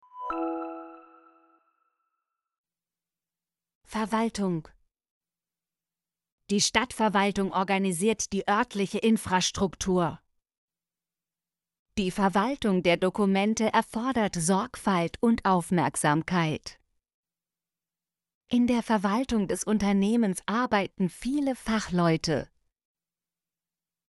verwaltung - Example Sentences & Pronunciation, German Frequency List